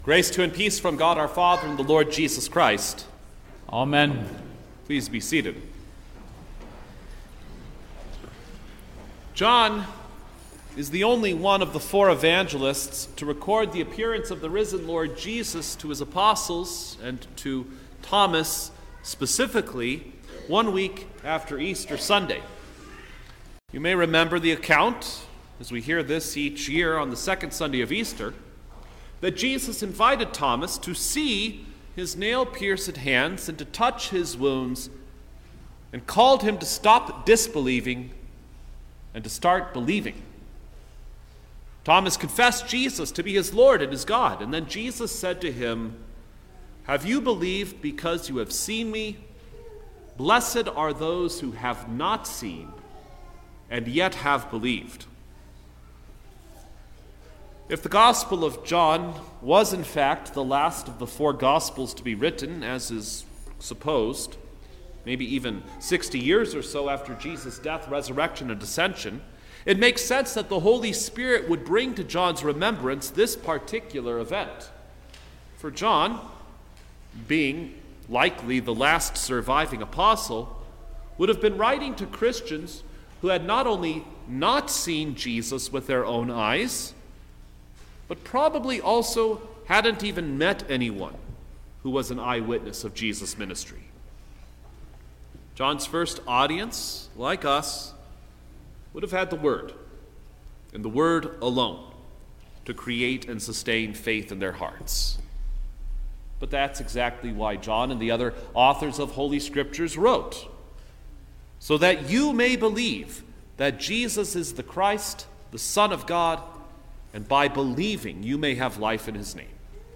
November-5_2023_All-Saints-Day_Sermon-Stereo.mp3